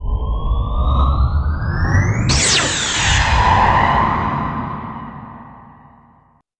RomulanEnterWarp.wav